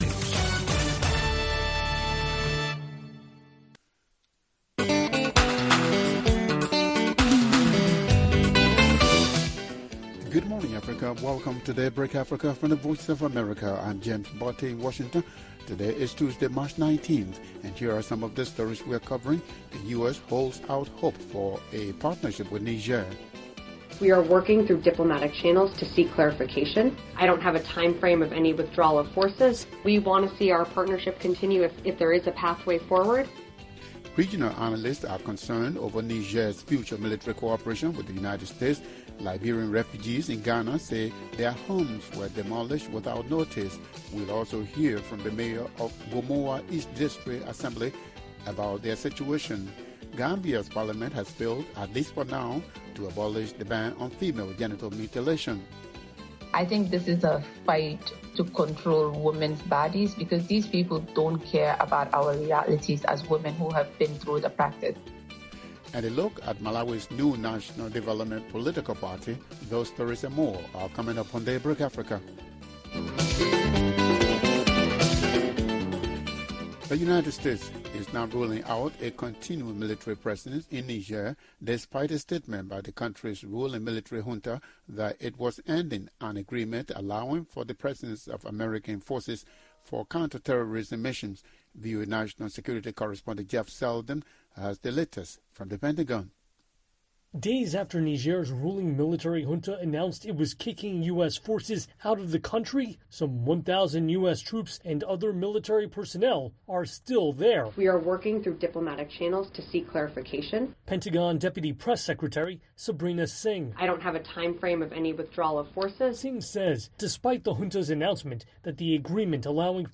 Liberian refugees in Ghana say their homes were demolished without notice. We’ll also hear from the mayor of Gomoa East District Assembly about the situation. Gambia’s parliament has failed, at least for now, to abolish the ban on female genital mutilation.